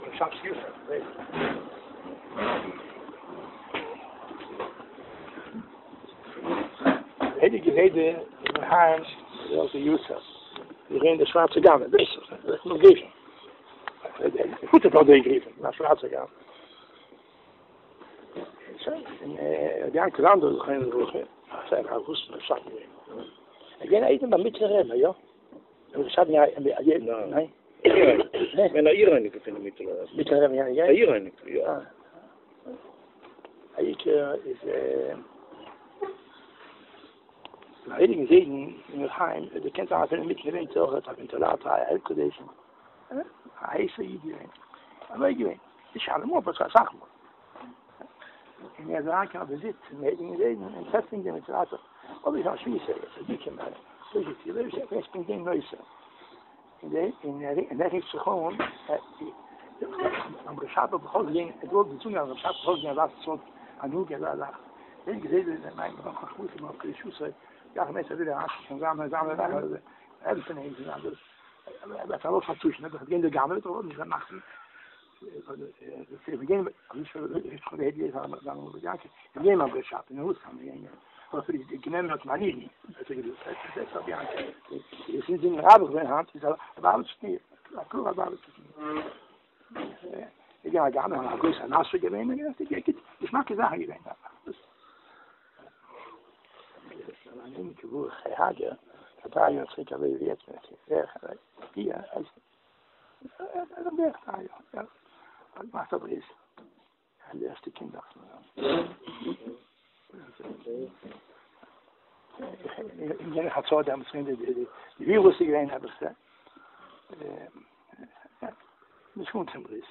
הקלטה: דברי מרן רבינו שליט"א היום אחר תפילת שחרית - ויז'ניצע נייעס
דברי מרן רבינו שליט"א הבוקר יום שישי ב ניסן אחר תפילת שחרית על רביה"ק בעל האמרי חיים זיעועכי"א לרגל שבת יארצייט